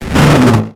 Cri de Malosse dans Pokémon X et Y.